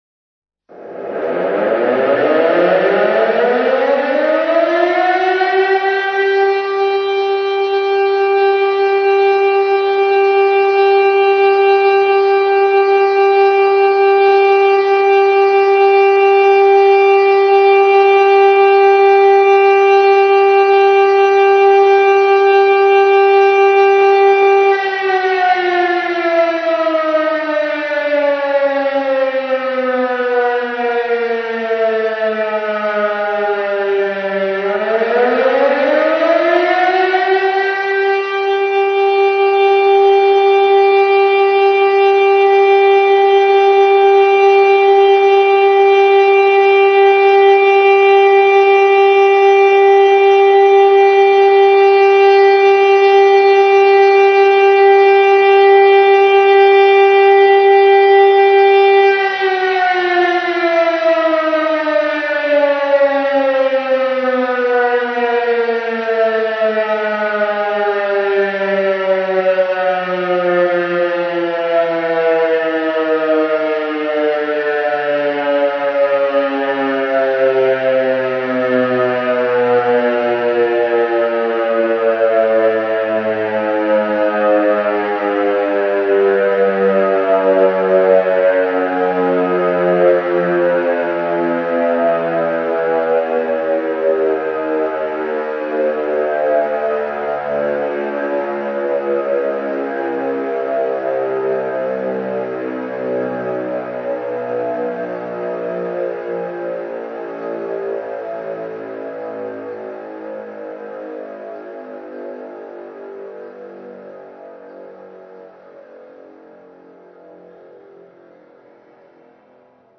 Sirény
POŽÁRNÍ POPLACH
pozar.mp3